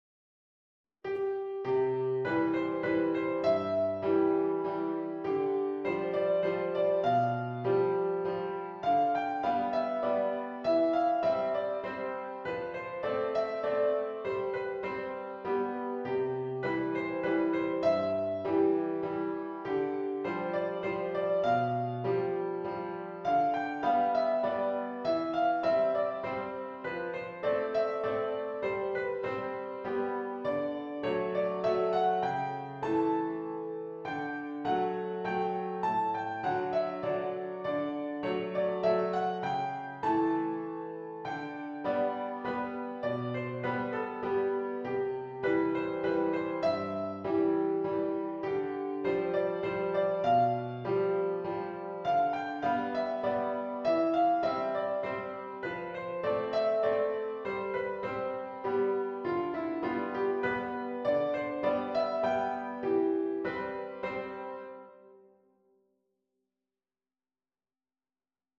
An early classical Waltz - Piano Music, Solo Keyboard - Young Composers Music Forum
An early classical Waltz